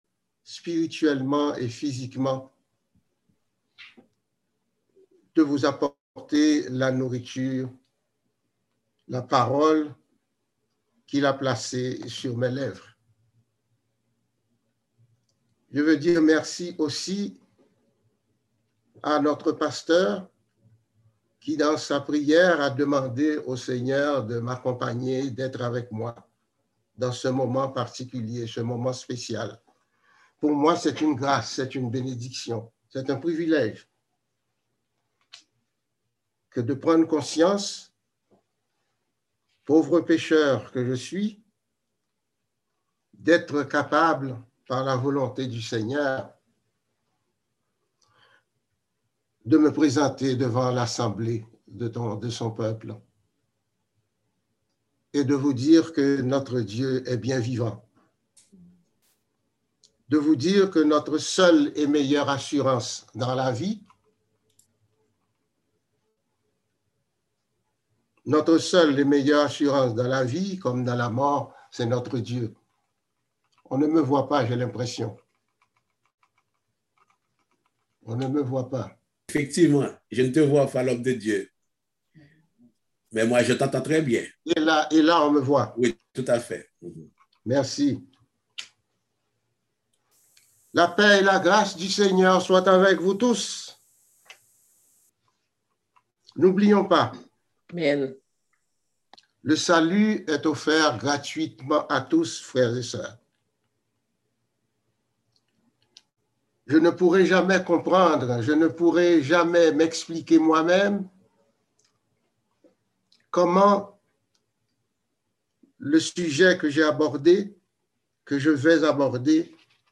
Passage: Matthieu 7: 12-28 Type De Service: Dimanche matin